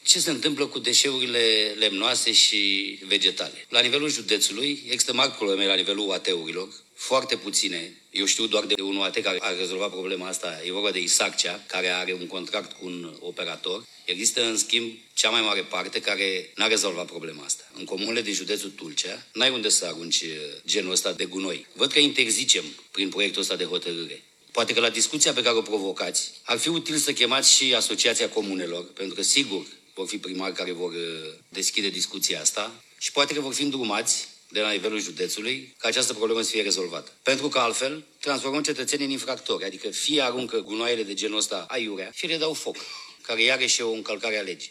În acest context, Dan Lucian Bălan, consilier județean din partea partidului AUR, a atras atenția asupra lipsei de alternative viabile pentru cetățeni în ceea ce privește gestionarea deșeurilor lemnoase și vegetale: